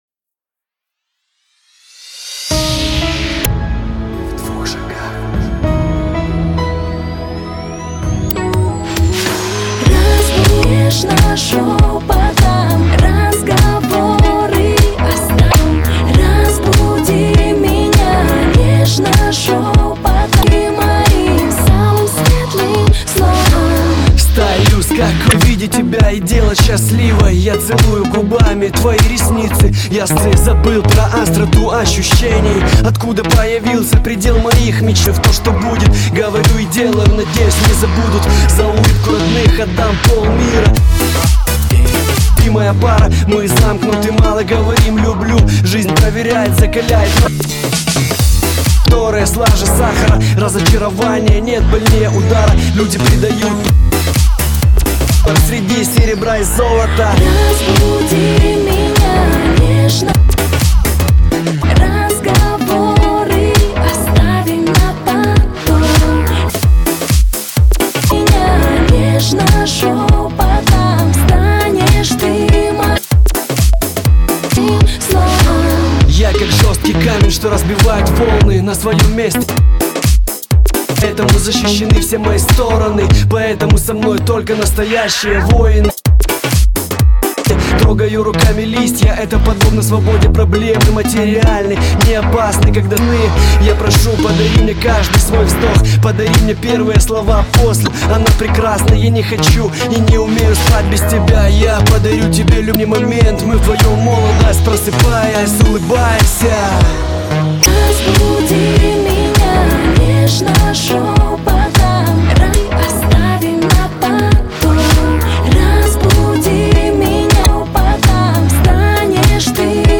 На мобилку » Mp3 » Рэп музыка